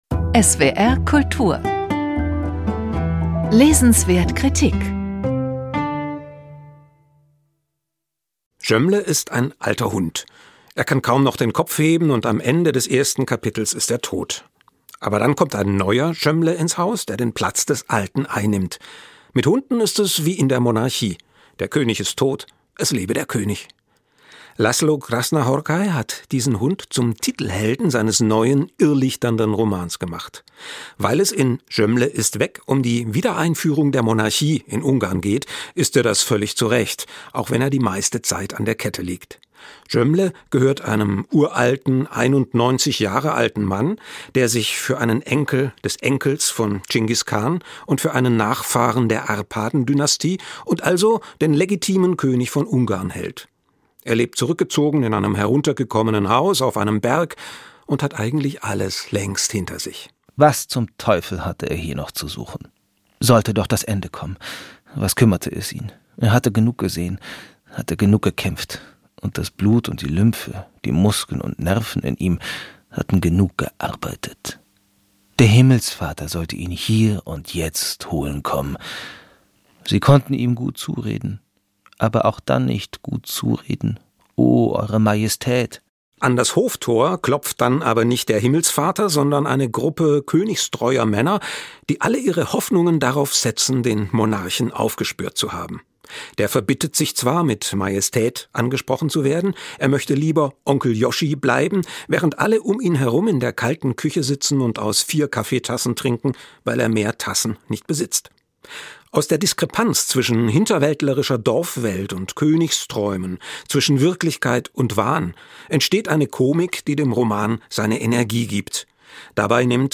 Rezension